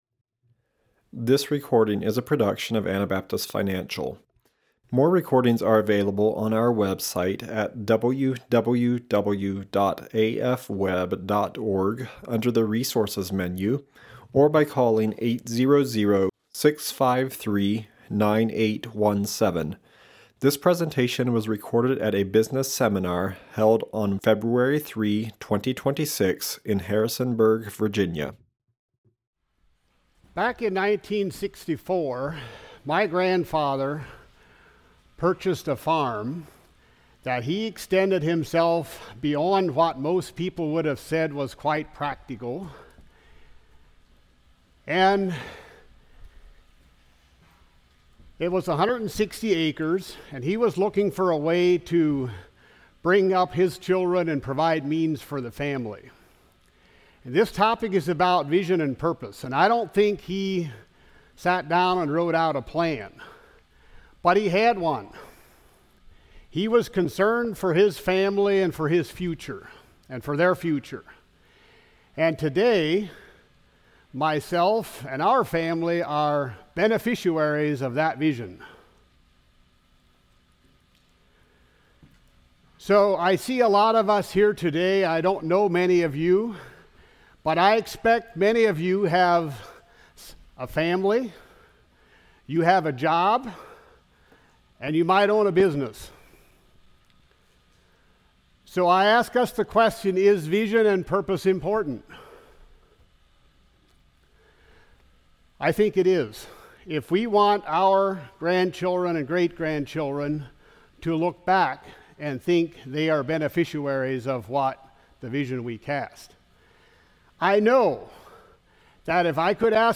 Virginia Business Seminar 2026